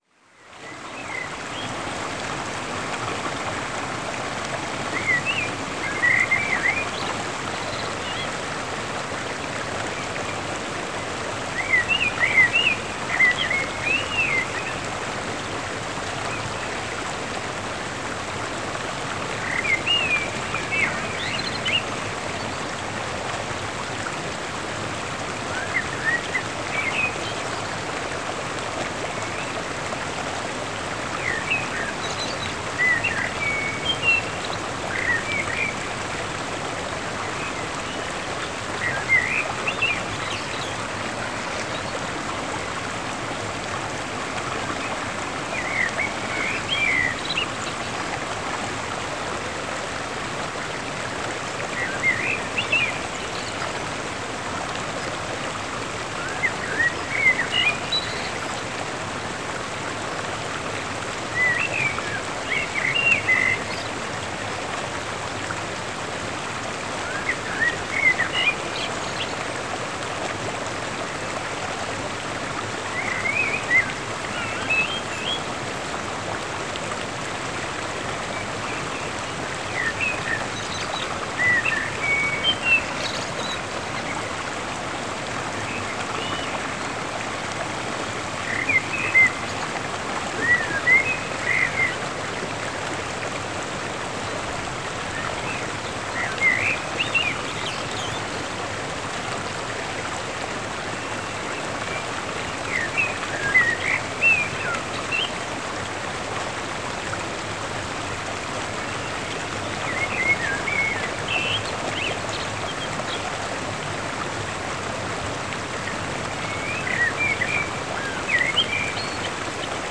Enjoy more ambient sounds of soft flowing water and gentle bird songs to help you drift off to sleep, or to simply help you relax at the end of a long day of modern living.
This audio was captured in the remote north-west of Scotland where the highlands meet the sea and the Glens give way to some of the worlds most beautiful untouched beaches.
The magical sounds of our mountain rivers as they tumble their way to the North Atlantic, and birds and native wildlife that greet each new day with their morning music, I hope you find the same peace and balance in these sounds of the natural world as I do.
Title: Relaxing River Sounds With Birds
relaxing-river-birds-10m.mp3